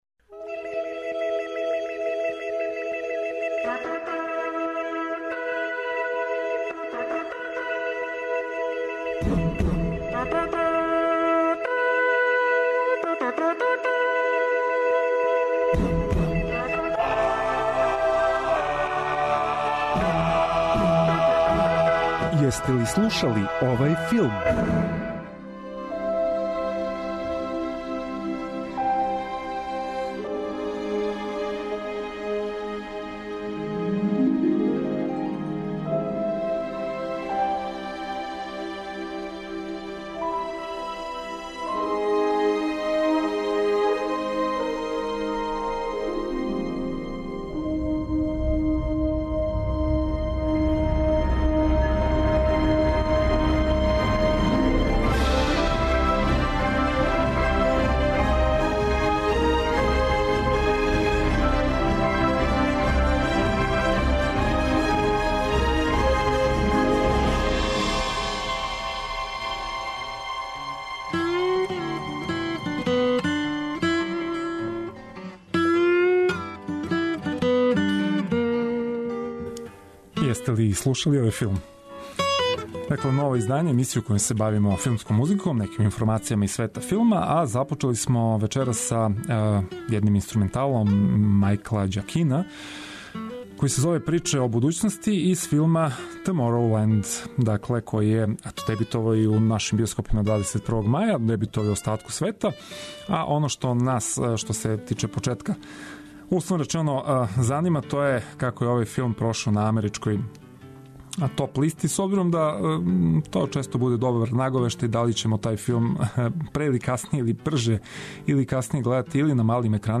Филмска музика и филмске вести. Заједно ћемо се присетити мелодија које ће нам вратити у сећање сцене из филмова, али и открити шта нам то ново спремају синеасти и композитори.